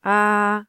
雅文檢測音
a_-15db.mp3